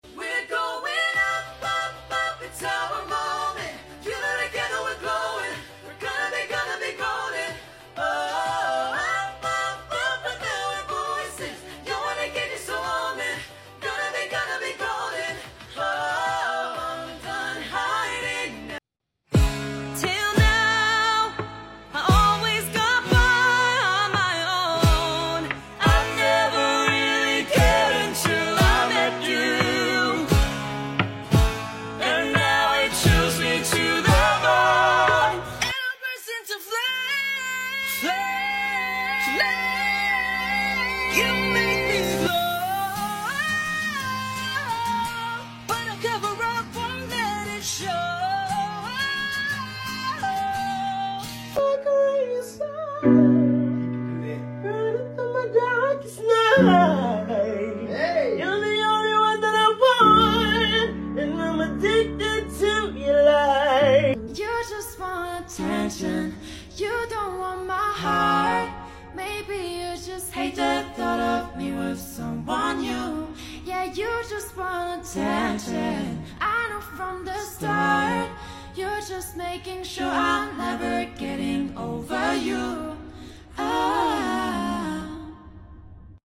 Top 5 Unexpected Singing sound effects free download